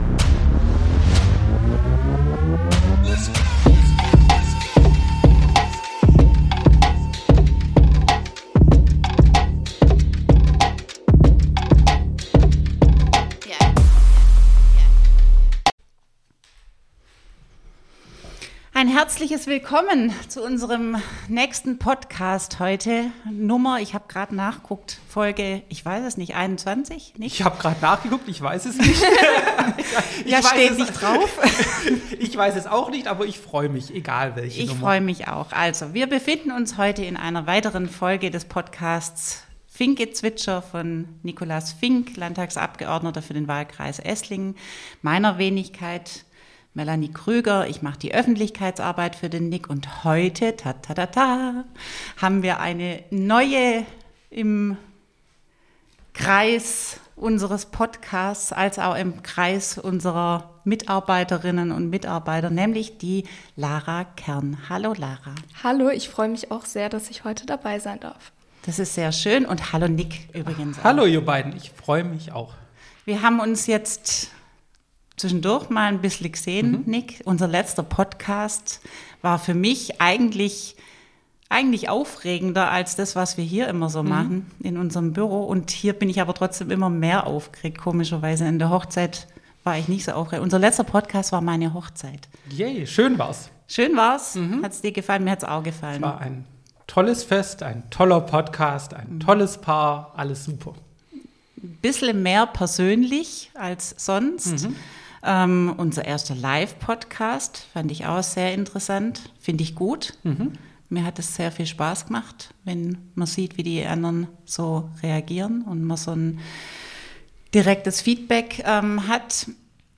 Eigentlich ist es ein Gespräch ohne festen Plan und doch wird es, wie immer im FINKGezwitscher, schnell auch politisch. Die Themen reichen von Landschaften, Waschmaschinen, Urlaub bis hin zu Landtag, Social Media, Universitäten und leider auch weniger angenehmen Begegnungen.